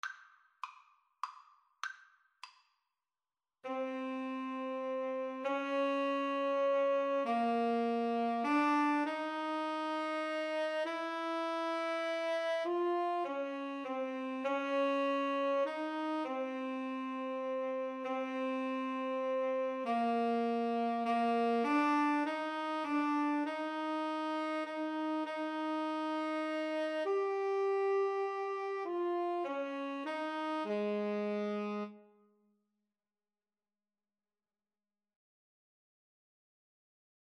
Moderato
3/4 (View more 3/4 Music)